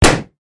zhadanbaozha.mp3